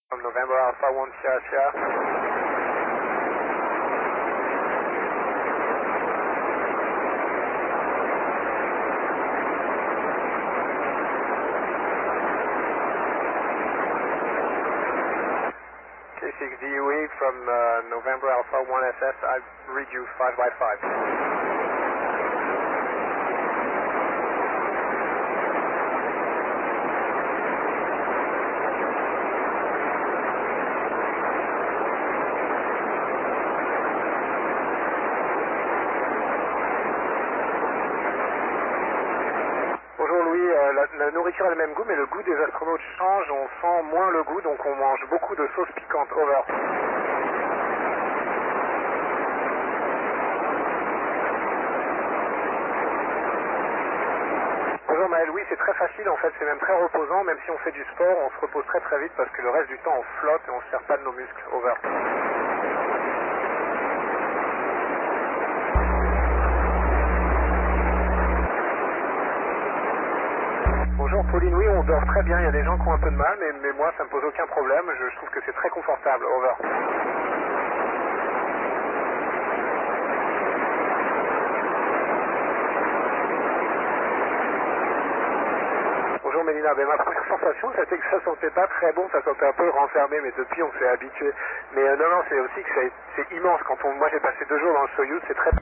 iss crew voice